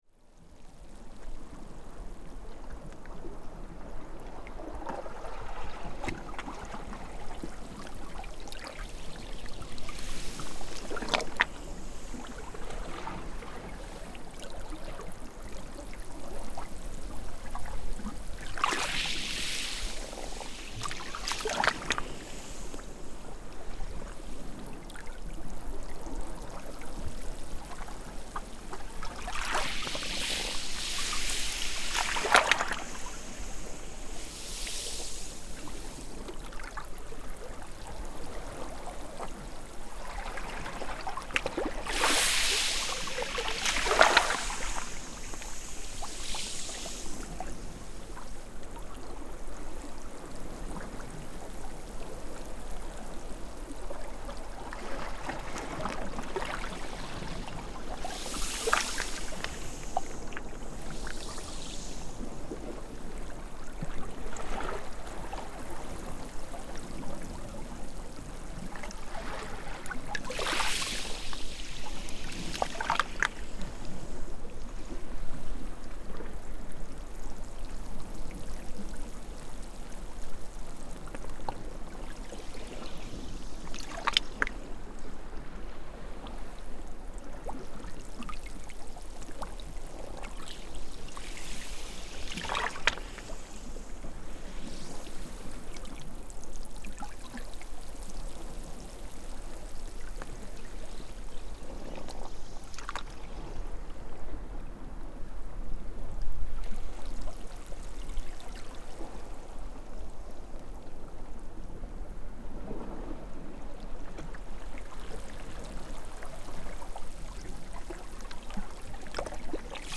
Geophonia: Soothing rain stick
On the rocks of Treffiagat beach, I point the shotgun microphone at a puddle of sea water that is gradually filling up with the rising tide.
You can hear the sounds of the sand and tiny pebbles following the surf in a relaxing, exhilarating effect… like a rain stick being turned very gently.